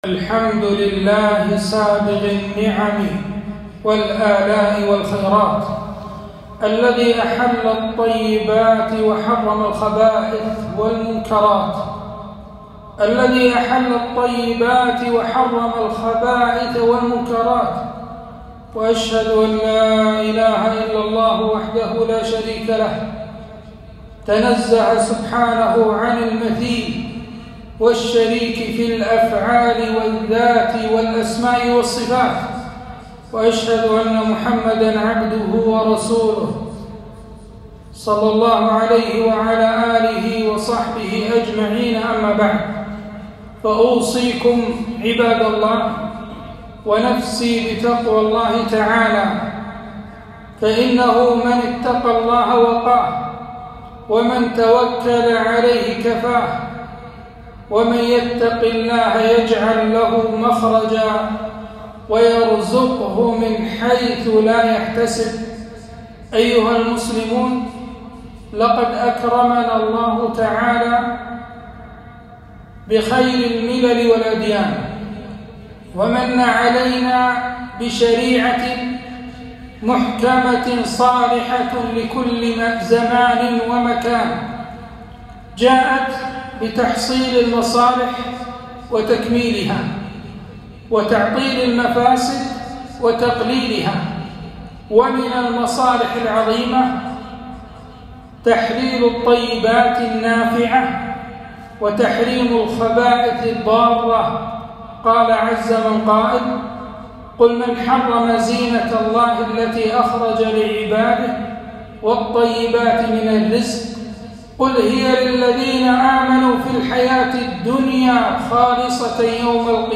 خطبة - رسالة إلى المدخنين